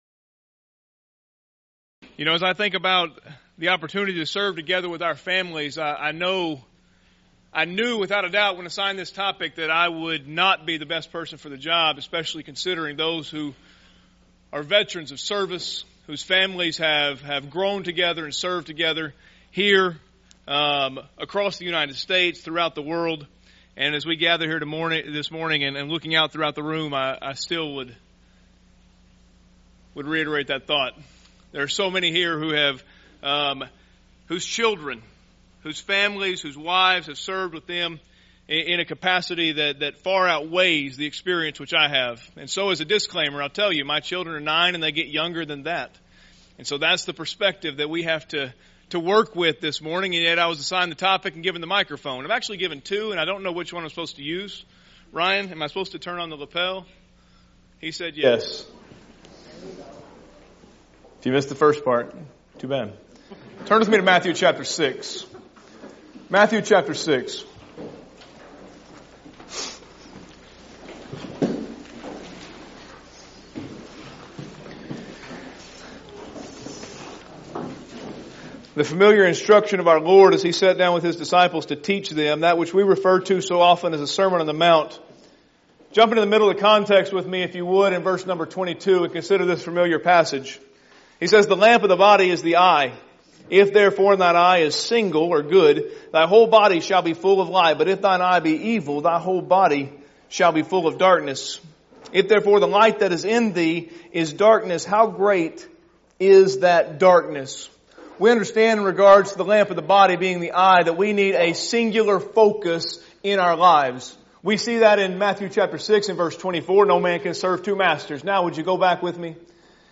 Event: 1st Annual Arise Workshop
lecture